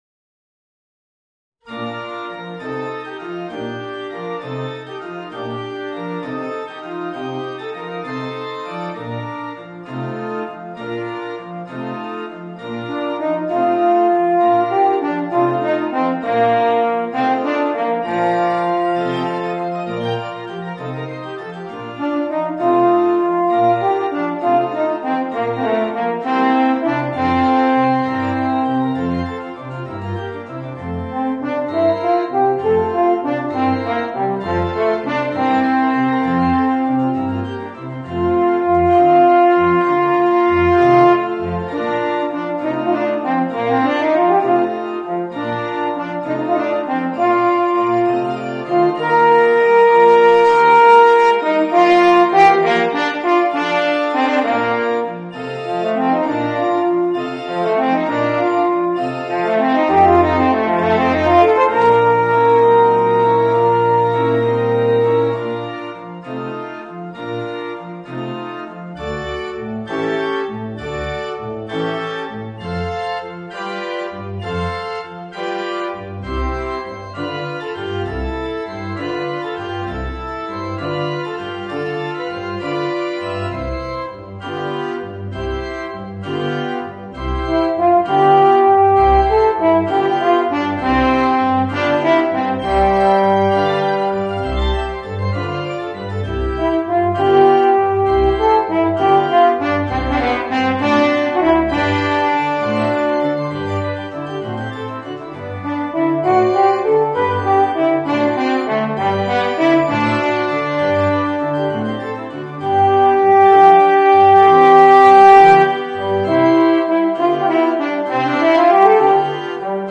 Voicing: Horn and Organ